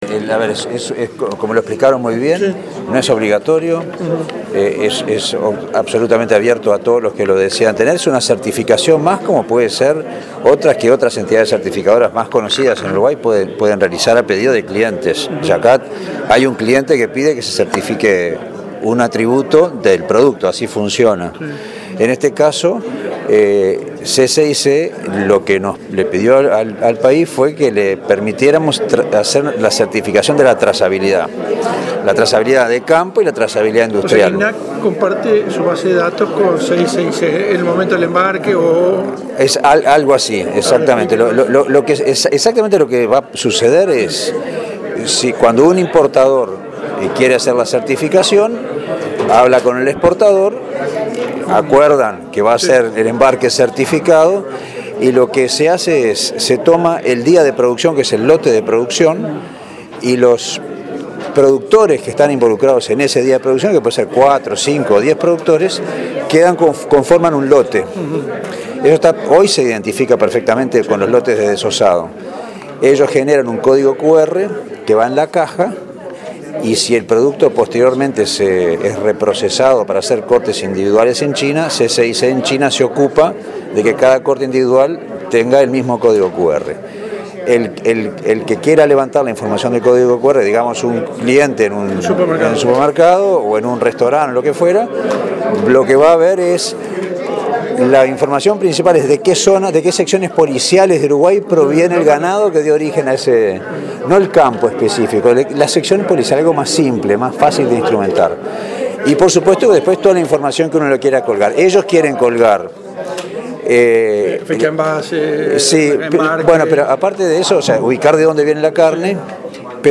Presidente de INAC. mp3. Gentileza Mundo Agrario 9:37